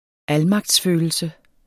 Udtale [ ˈalmɑgds- ]